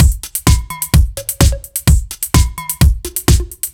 Index of /musicradar/french-house-chillout-samples/128bpm/Beats
FHC_BeatD_128-01.wav